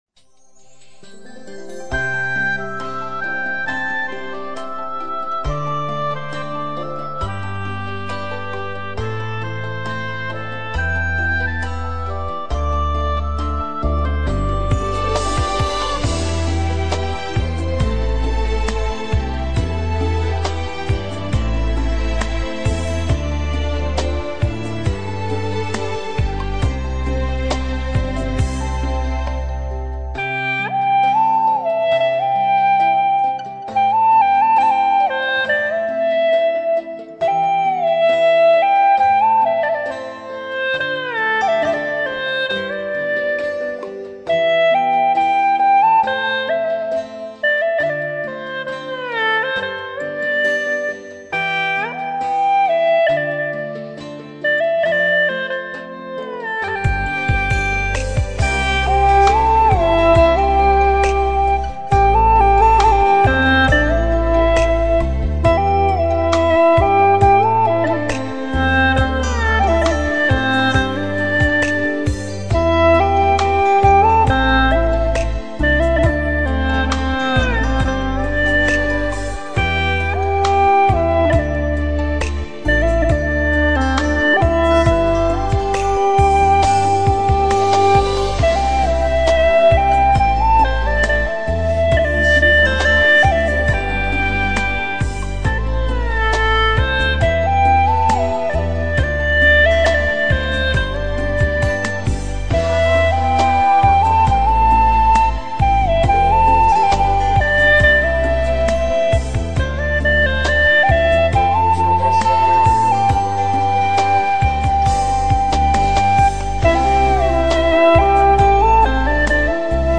调式 : C 曲类 : 流行